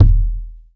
impact_deep_thud_bounce_06.wav